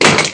DROPGUN2.mp3